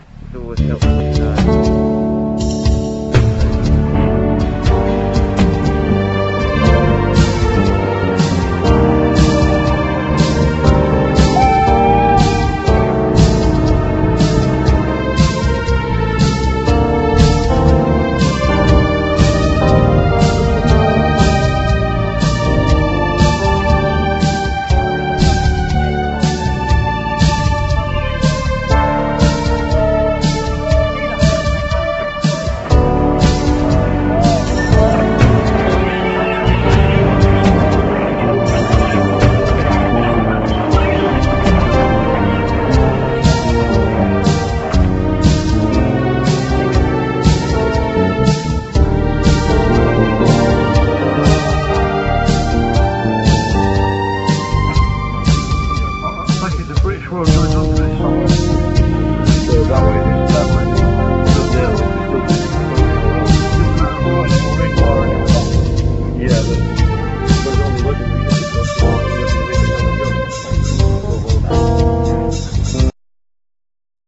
Studio recorded track